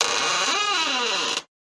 creaking.mp3